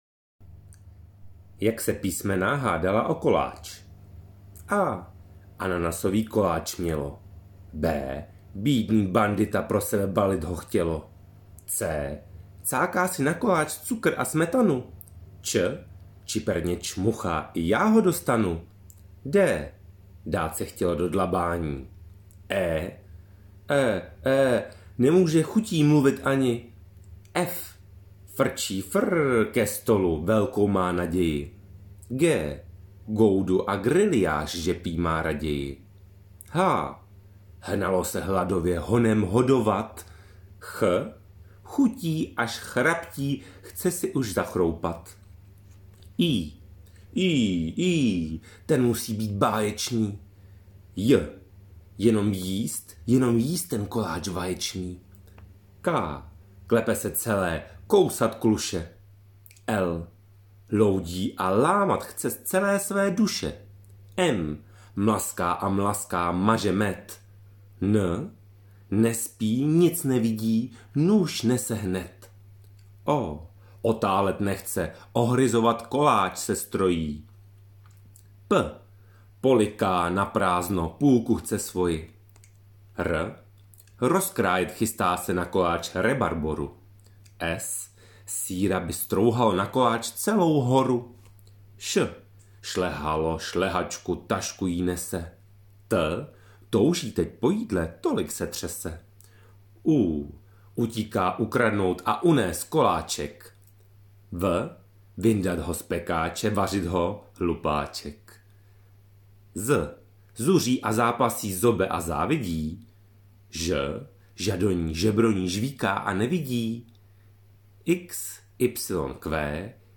Pohádka: Jak se písmena hádala o koláč